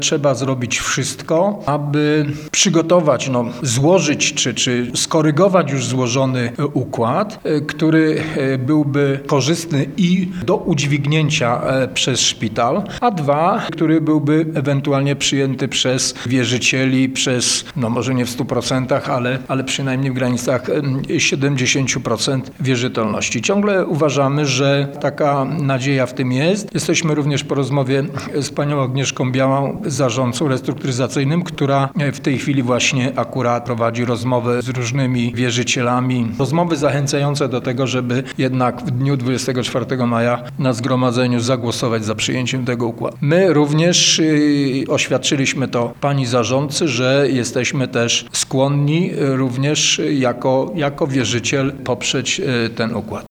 – Jako właściciel szpitala uważamy, że nie można dopuścić do sytuacji, że szpital znajdzie się w upadłości i to niekontrolowanej – podkreśla Wacław Strażewicz, starosta powiatu giżyckiego: